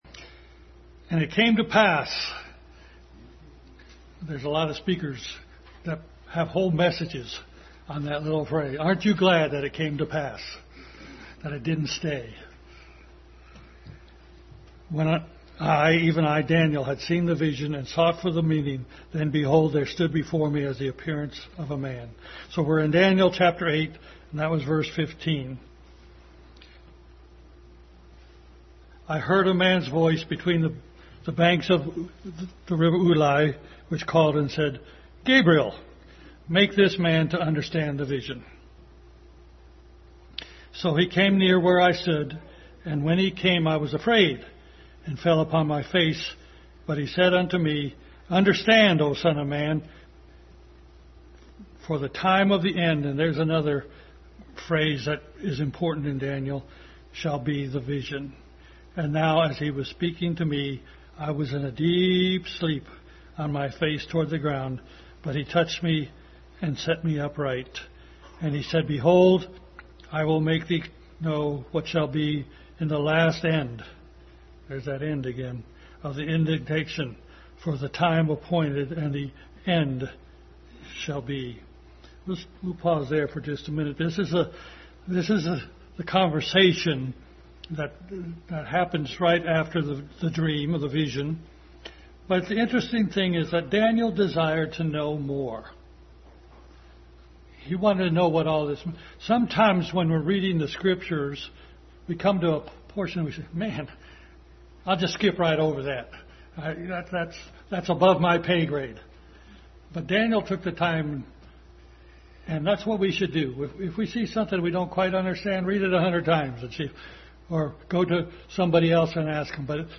Daniel 8:15-27 Passage: Daniel 8:15-27, Jude 9, Revelation 12:7, Daniel 12:4, 7:8 , 7:6, 8:13 Service Type: Family Bible Hour